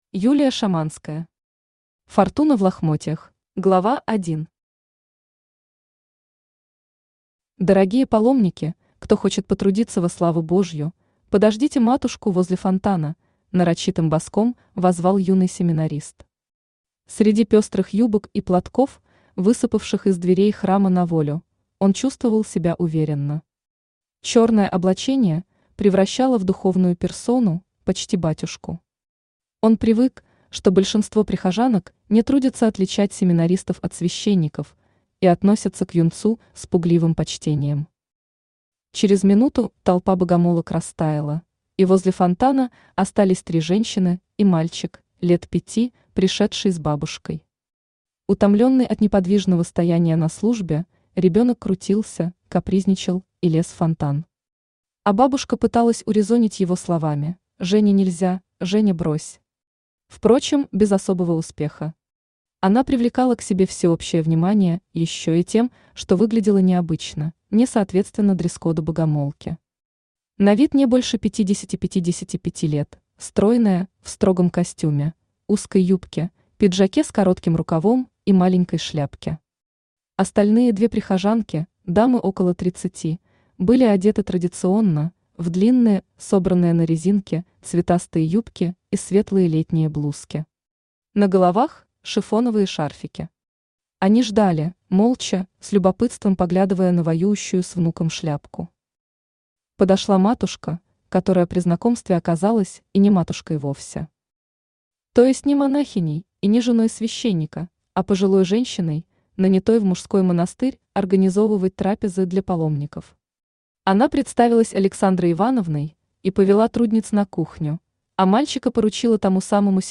Аудиокнига Фортуна в лохмотьях | Библиотека аудиокниг
Aудиокнига Фортуна в лохмотьях Автор Юлия Валерьевна Шаманская Читает аудиокнигу Авточтец ЛитРес.